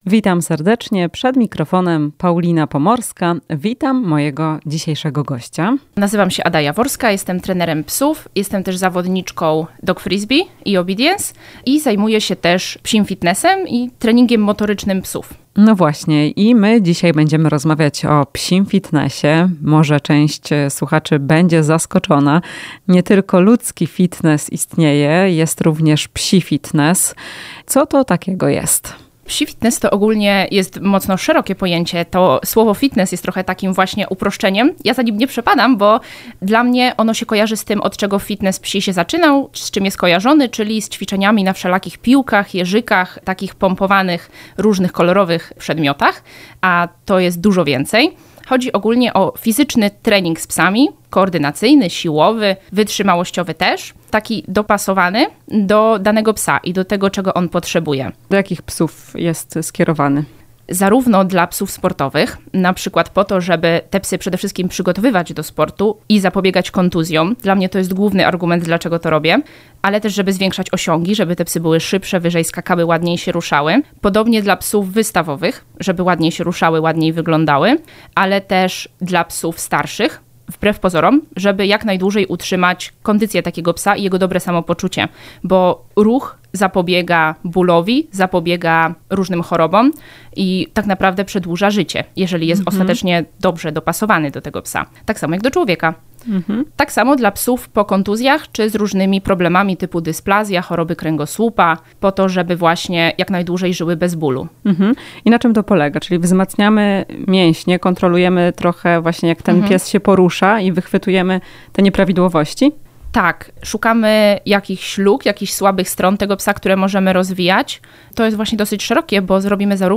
W "Chwili dla pupila" powiemy, czym jest psi fitness. Rozmowa